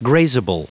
Prononciation du mot grazable en anglais (fichier audio)
Prononciation du mot : grazable